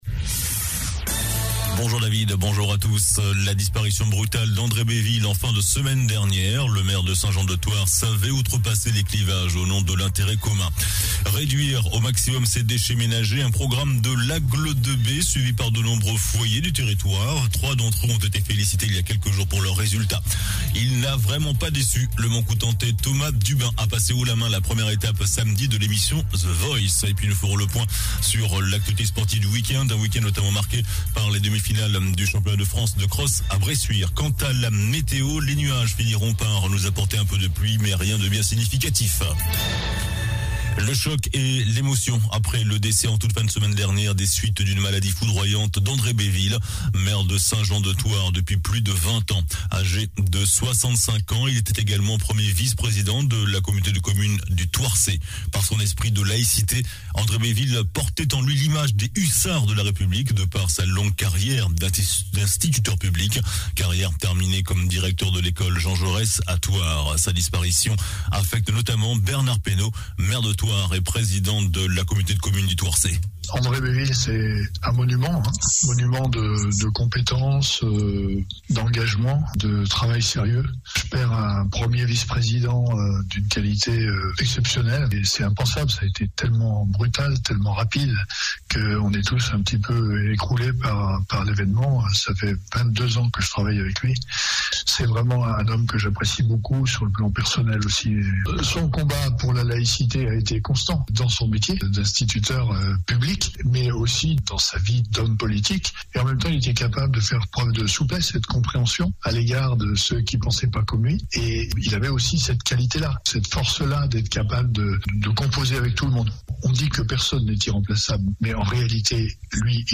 Journal du vendredi 21 janvier (midi)